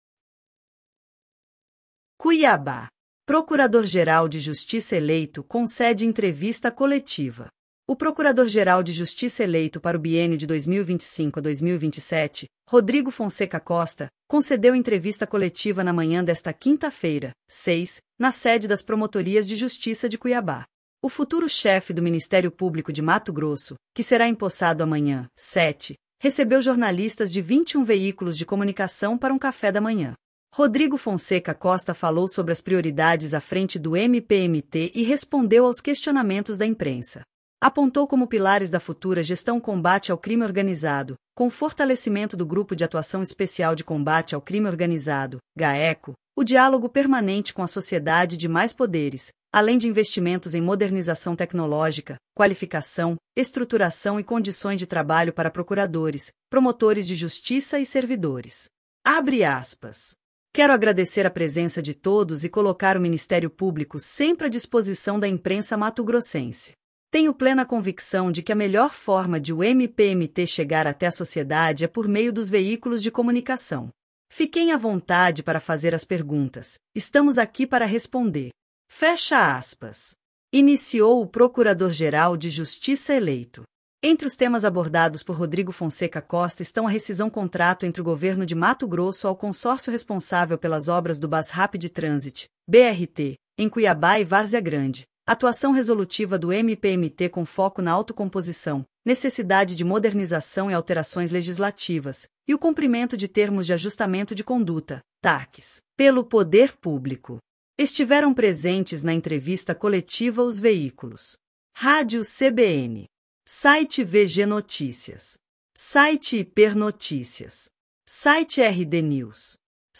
Procurador-geral de Justiça eleito concede entrevista coletiva
O procurador-geral de Justiça eleito para o biênio 2025-2027, Rodrigo Fonseca Costa, concedeu entrevista coletiva na manhã desta quinta-feira (6), na Sede das Promotorias de Justiça de Cuiabá.